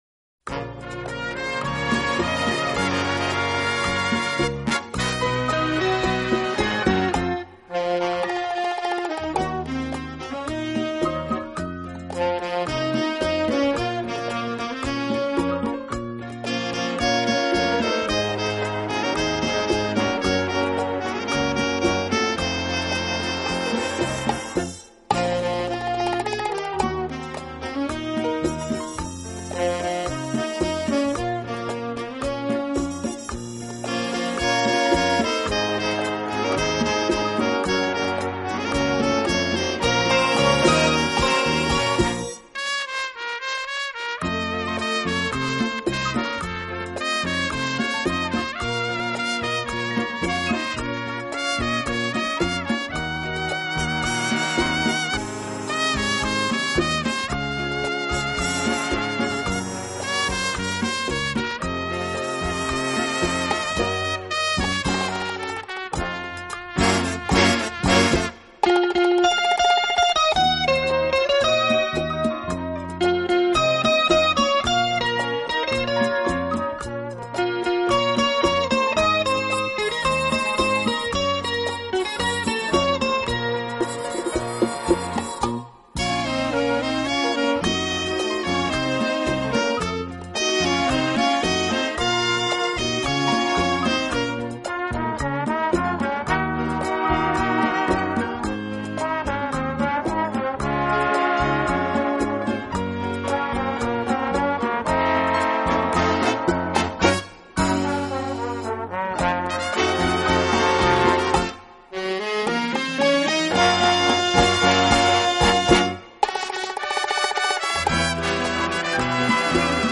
【舞曲专辑】
节奏为4/4拍，每分钟27～29小节。
乐曲旋律的特点是强拍落在每小节的第四拍。